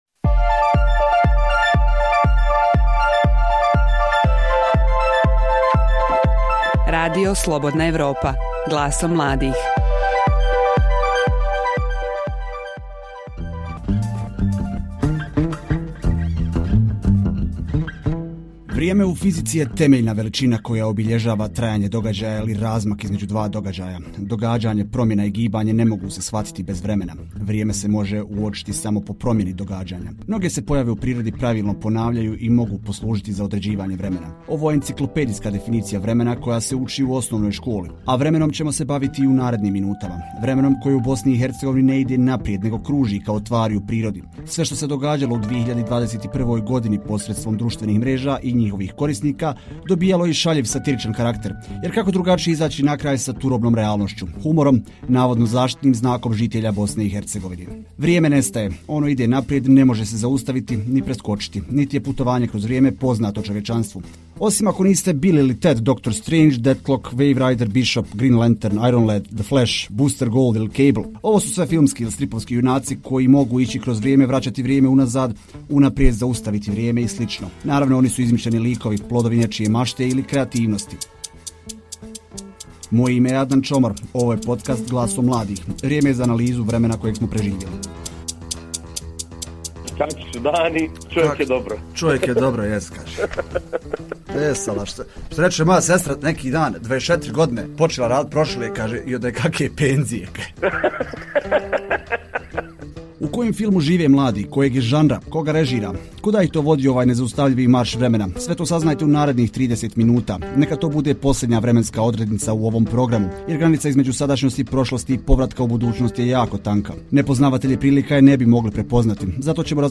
Sve što se događalo u 2021. godini u BiH – posredstvom društvenih mreža i korisnika dobijalo je i šaljiv, satiričan karakter. U podcastu Glasom mladih govore mladi i bivši mladi ljudi koji imaju pogled na stvarnost koja nas okružuje i kroji sudbine.